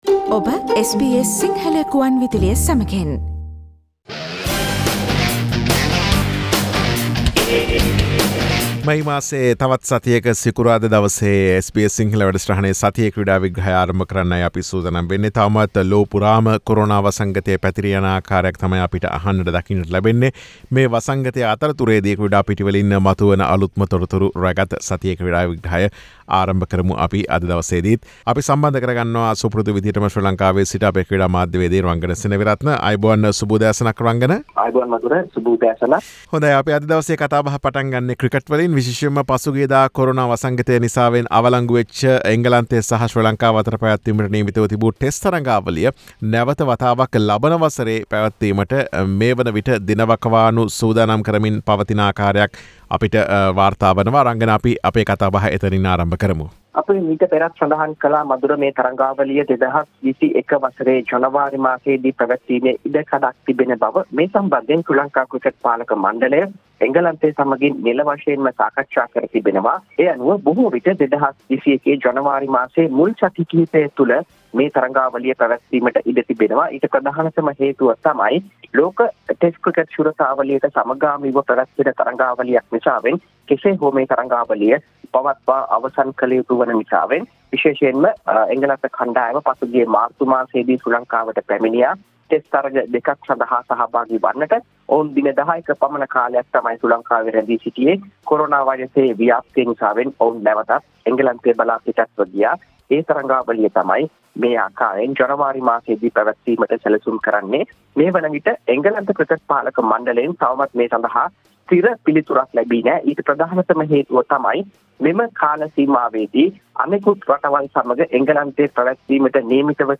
SBS Sinhalese Sports Wrap with Sports Journalist